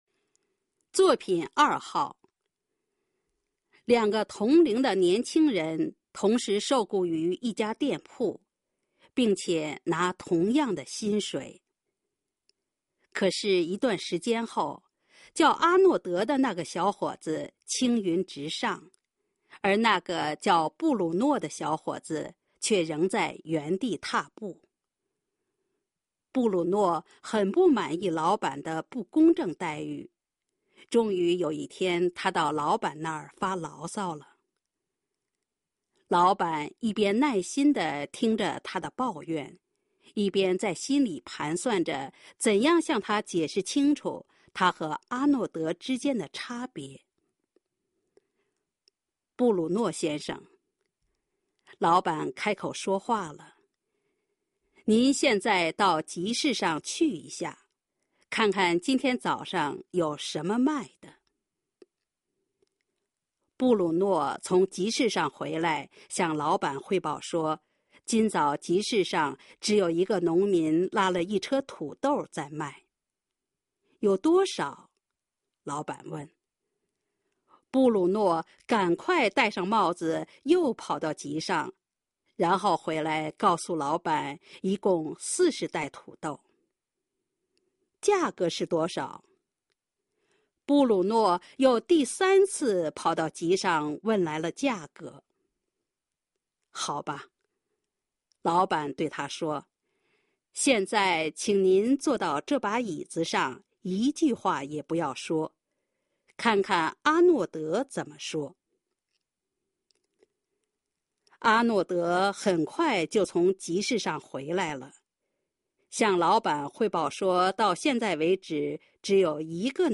当前位置：首页  教学服务与管理  语言文字  普通话测试资料  朗读作品
语音提示
1.店铺diànpù                         7.什么shénme
3.牢骚láo·sāo                       9.钟头zhōnɡtóu
6.清楚qīnɡchu                        12.便宜piányi